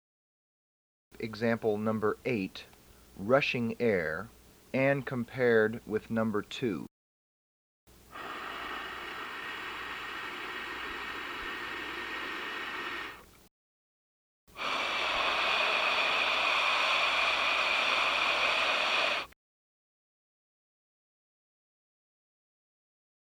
RUSHING AIR ONLY – EXAMPLE #8 is analogous to #2
SQUAWK/FLAT PITCH – EXAMPLE #9A is analogous to #3
LOW PITCH – EXAMPLE #9B is analogous to #3